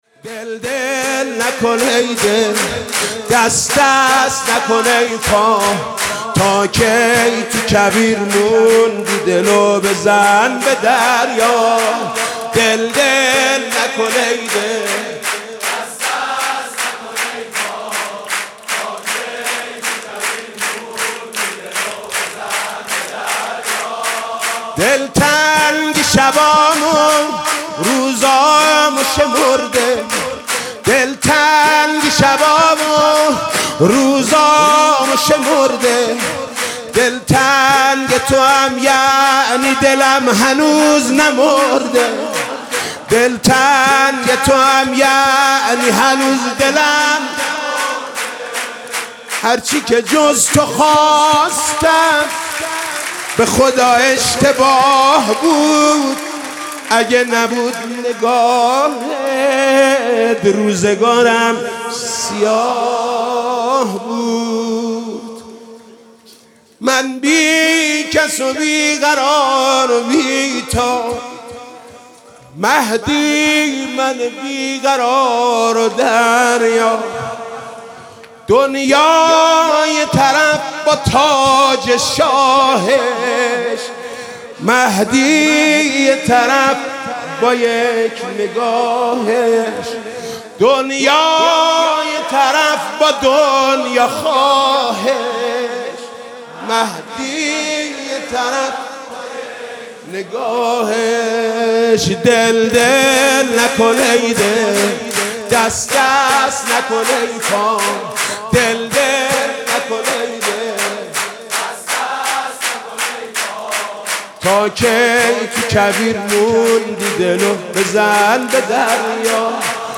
سرود: دل دل نکن ای دل